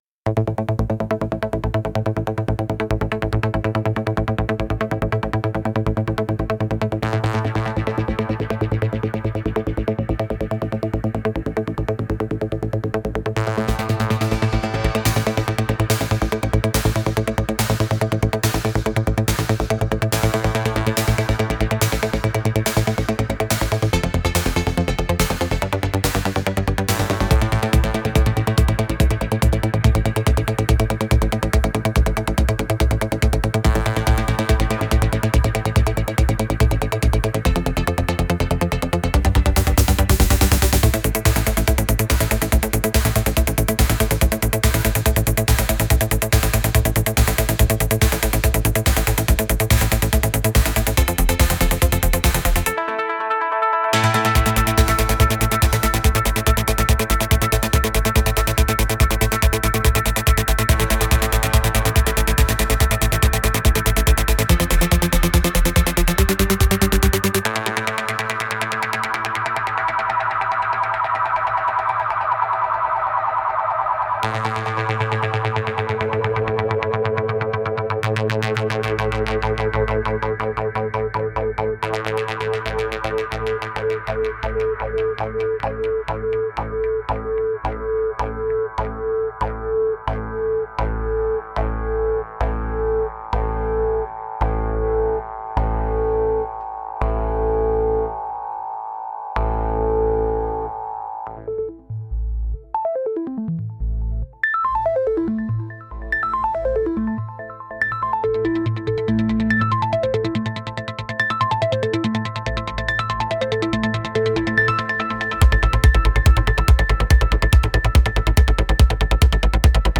Genre: Electronic Mood: Trance Editor's Choice